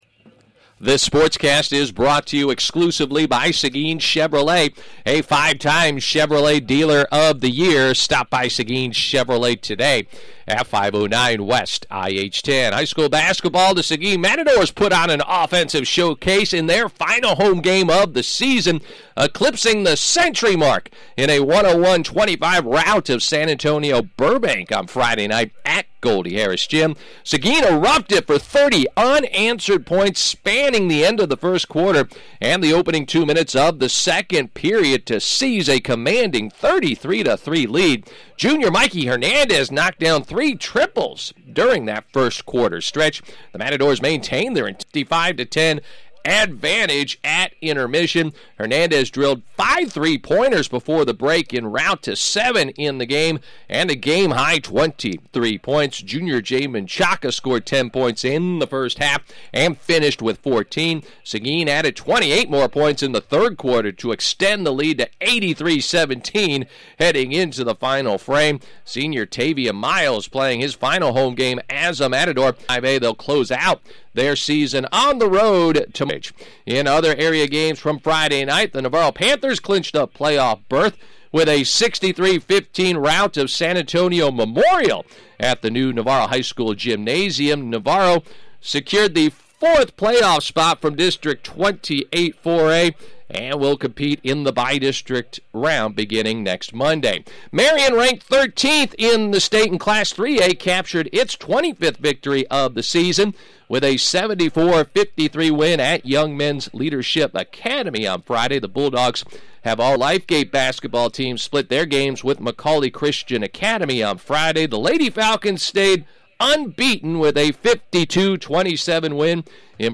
Monday 2-16 Sportscast